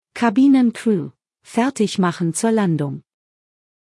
CrewSeatsLanding.ogg